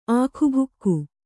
♪ ākhubhukku